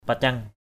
/pa-cʌŋ/ (đg.) che, chắn= cacher, couvrir. mâk tangin paceng mbaok mK tz{N pc$ _O<K lấy tay che mặt. paceng khik palei nagar pc$ A{K pl] ngR bảo vệ xóm làng....
paceng.mp3